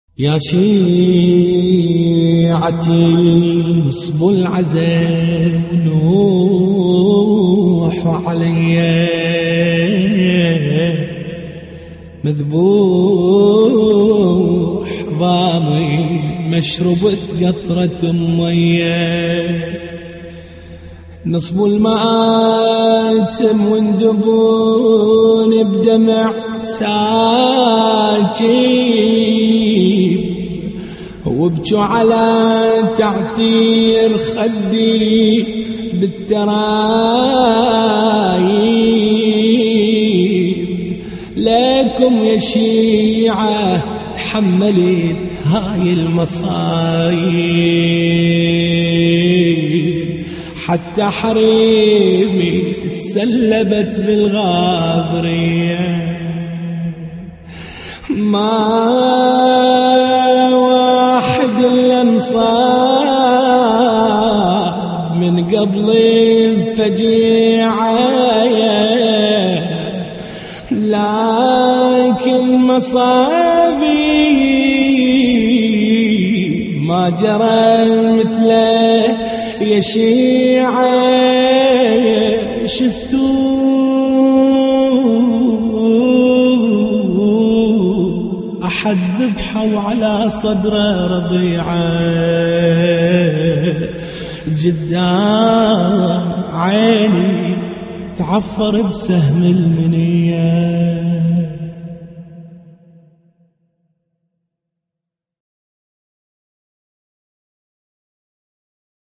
نعي